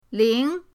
ling2.mp3